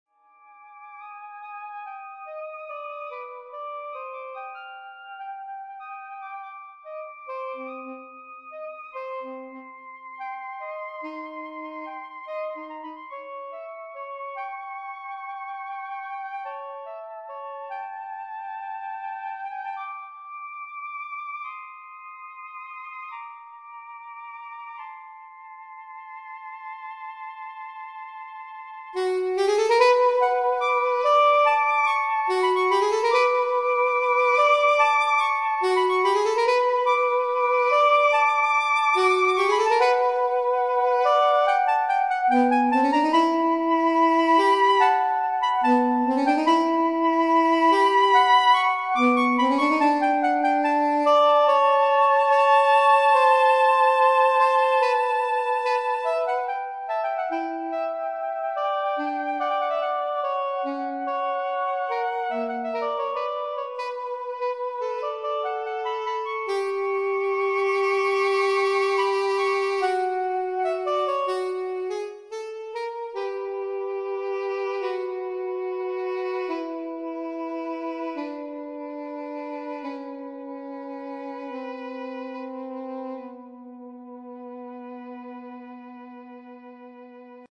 Duo/Trio/Quartett für gleichwertige Saxophone.